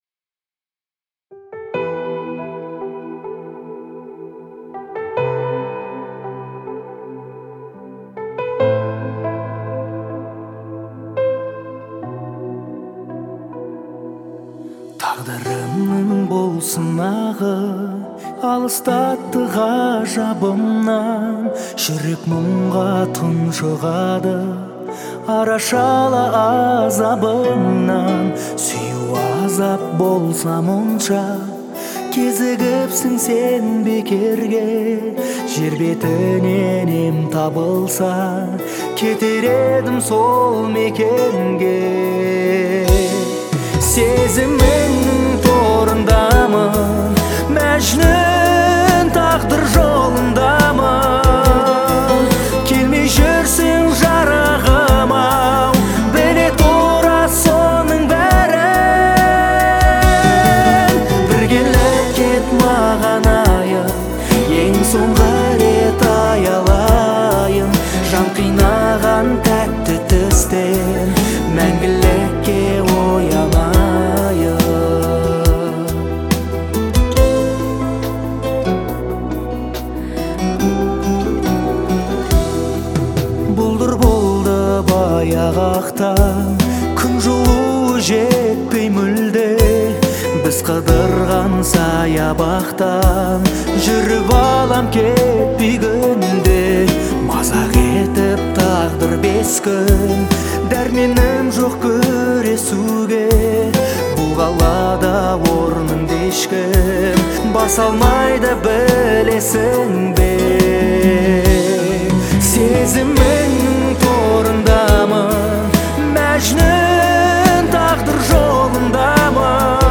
это эмоциональная песня в жанре казахской поп-музыки